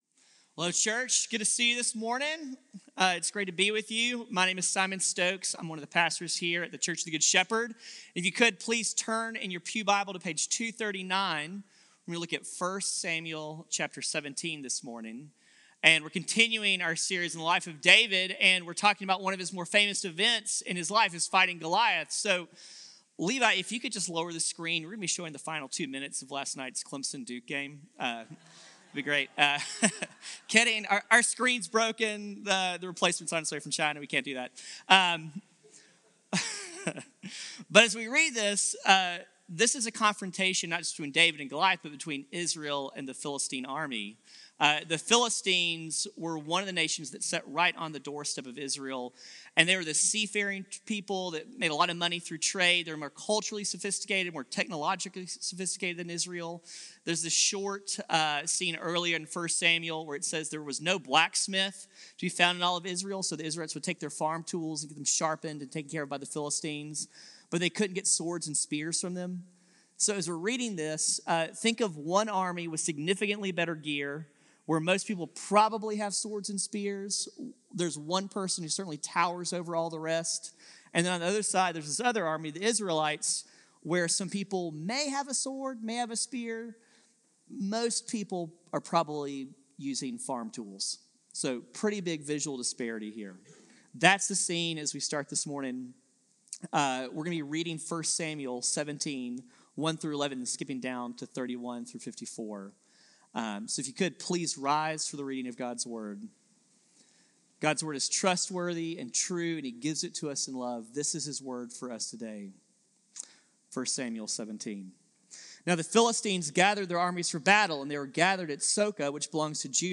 CGS-Service-2-9-24.mp3